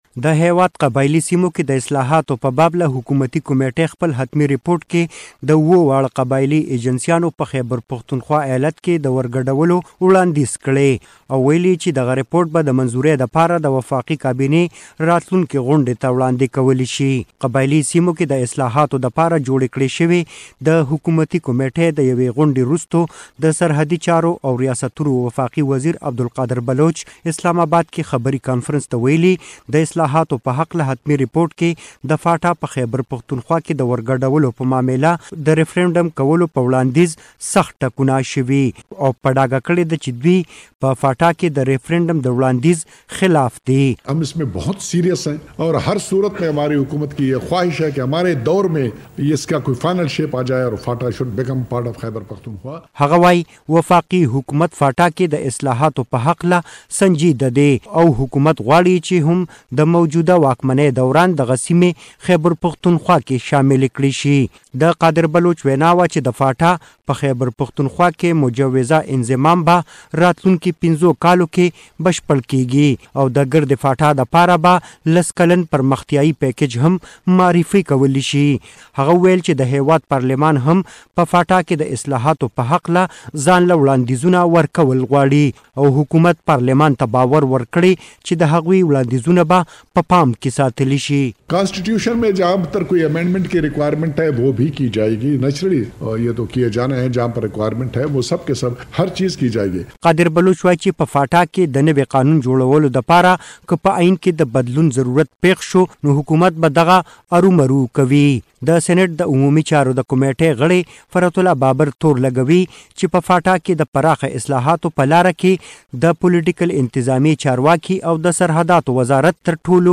report on Abdul Qadar Baluch media talks